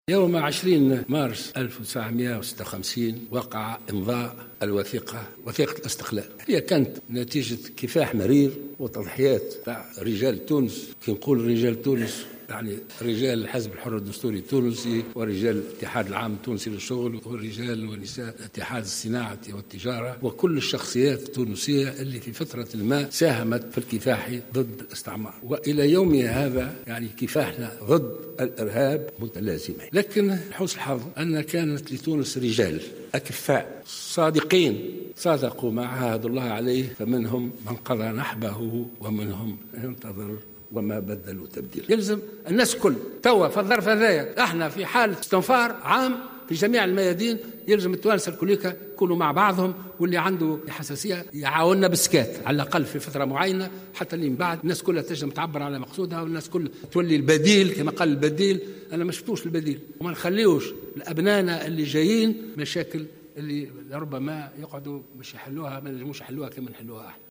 قال رئيس الجمهورية الباجي قايد السبسي في خطاب ألقاه اليوم الأحد 20 مارس 2016 في القصر الرئاسي بقرطاج بمناسبة إحياء الذكرى الـ60 لعيد الاستقلال إنه لا بديل للتونسيين اليوم عن الوحدة الوطنية خاصة في ظل التحديات التي تعيشها البلاد وفي مقدمتها مواجهة الارهاب.